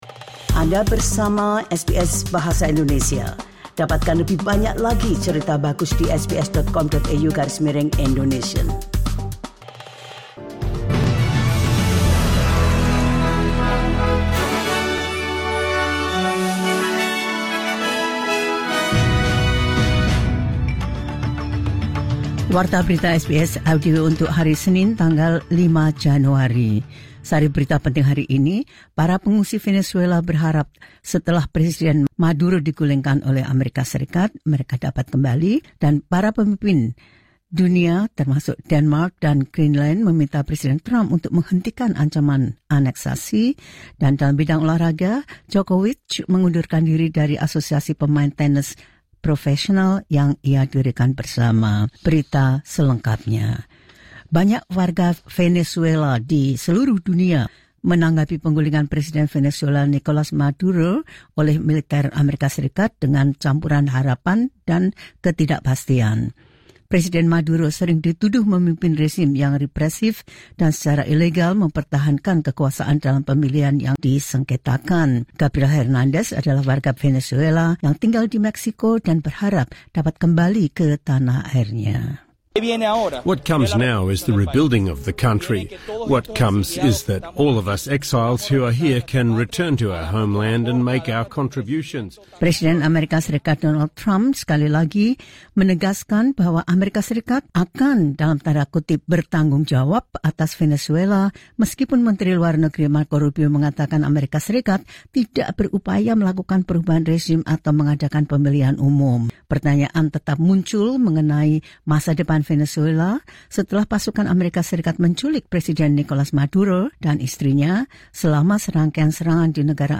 Berita terkini SBS Audio Program Bahasa Indonesia – 05 Januari 2026
The latest news SBS Audio Indonesian Program – 05 January 2026.